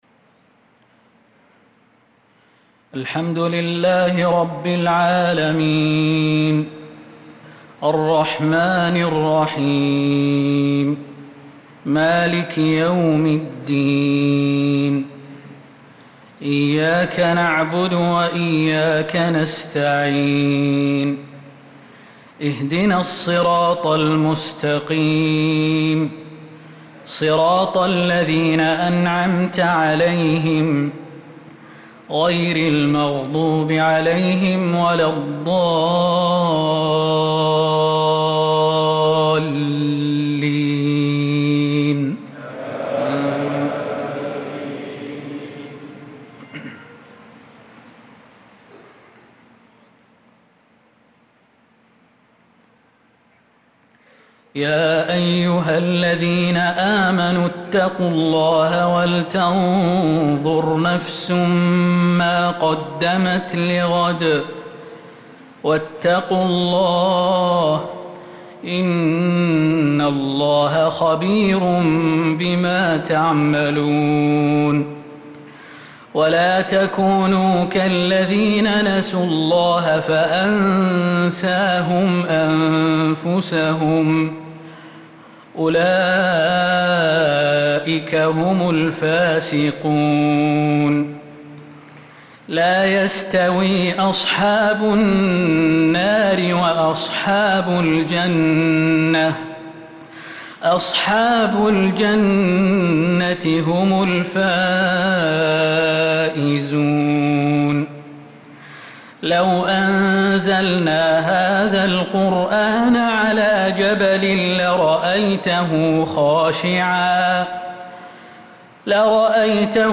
صلاة العشاء للقارئ خالد المهنا 26 ذو القعدة 1445 هـ
تِلَاوَات الْحَرَمَيْن .